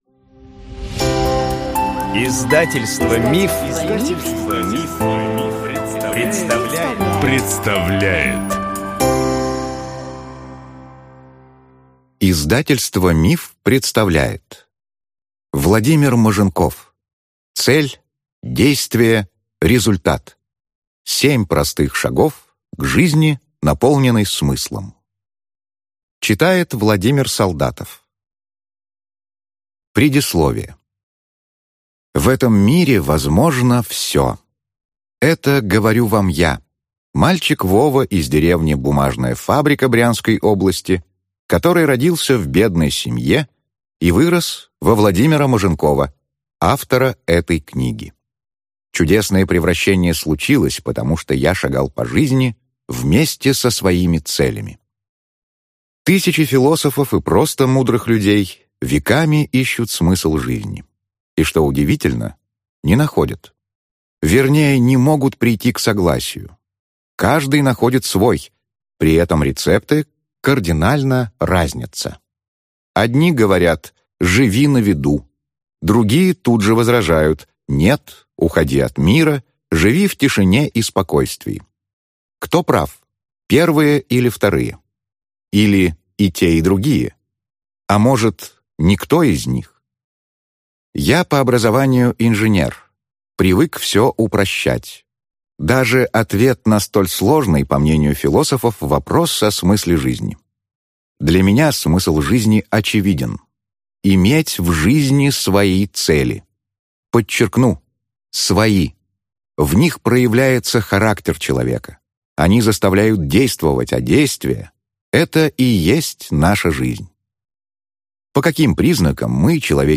Аудиокнига Цель-Действие-Результат. 7 простых шагов к жизни, наполненной смыслом | Библиотека аудиокниг